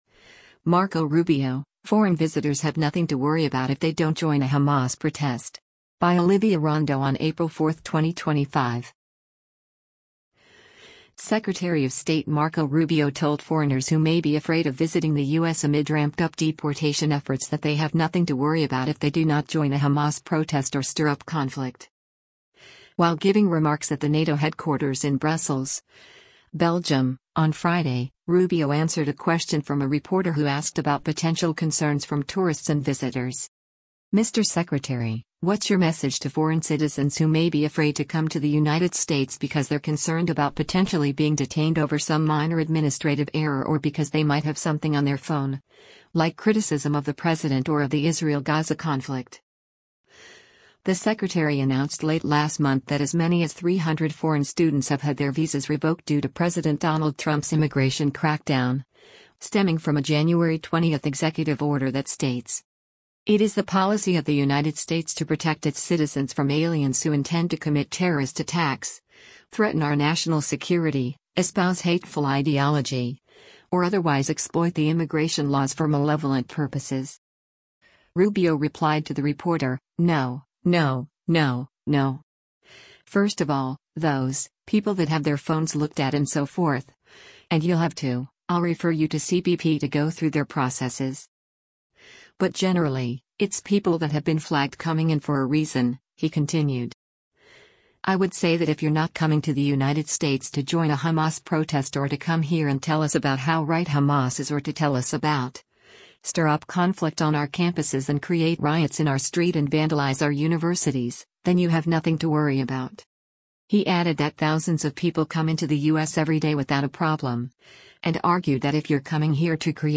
While giving remarks at the NATO headquarters in Brussels, Belgium, on Friday, Rubio answered a question from a reporter who asked about potential concerns from tourists and visitors: